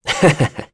Esker-Vox_Happy1.wav